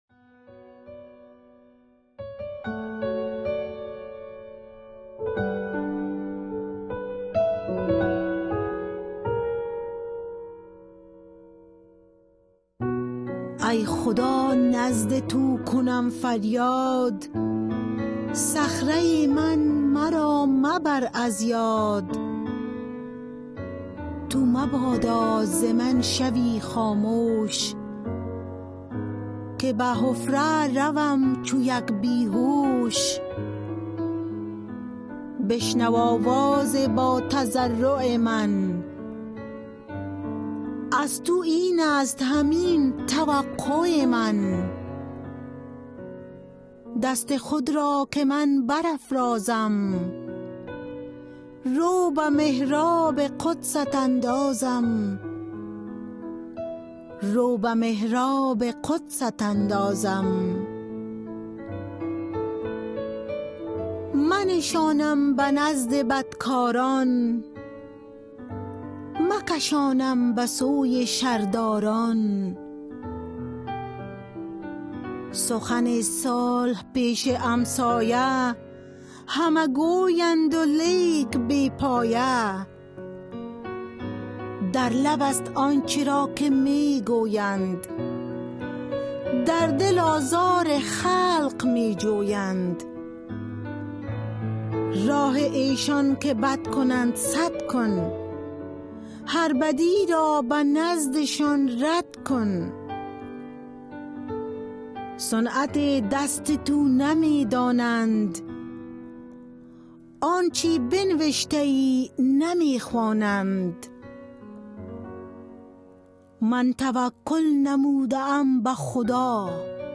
Poem Psalm 28